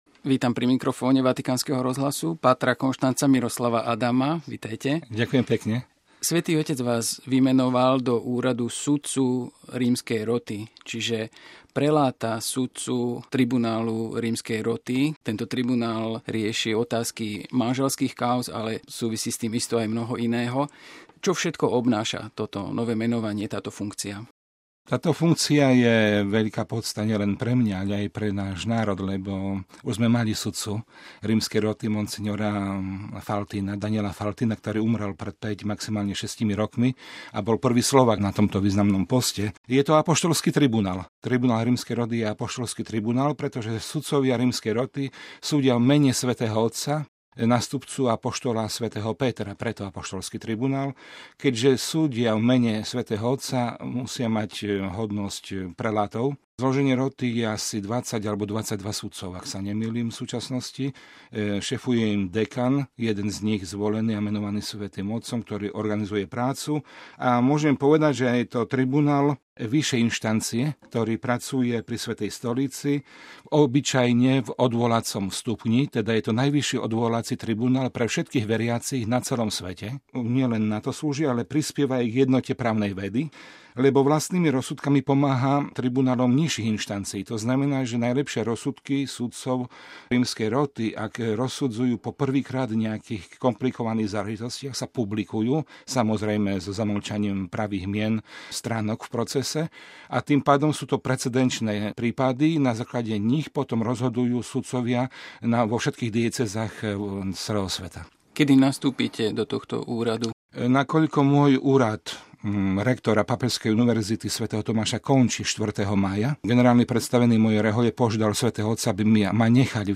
Rozhovor s novomenovaným sudcom Rímskej roty Konštancom M. Adamom OP
V súvislosti s dnes zverejneným menovaním poskytol páter Konštanc Miroslav Adam krátky rozhovor pre Vatikánsky rozhlas: